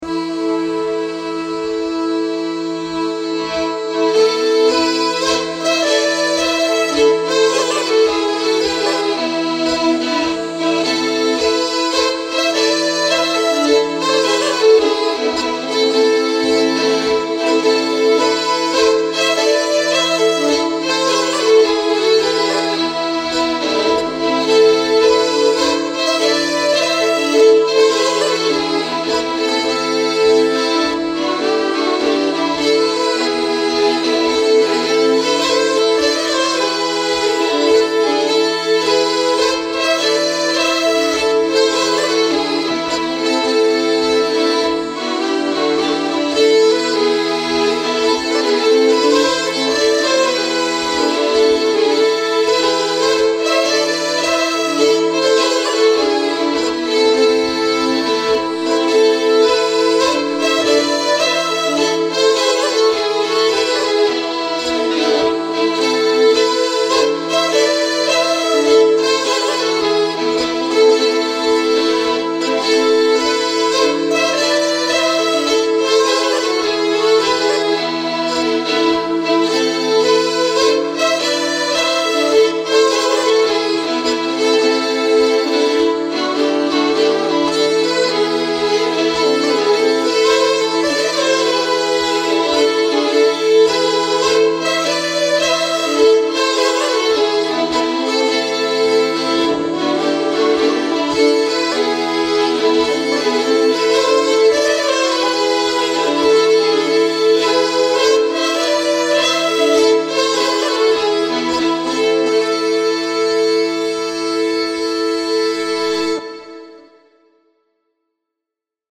Solokonsert i Vallentuna
En låt jag spelade på konserten i Vallentuna 6/4-2024 Polska efter Alfred Eriksson Västernäs Väddö.
Polska-efter-Alfred-Eriksson-Almsta-A-moll-sackpipa-arrat.mp3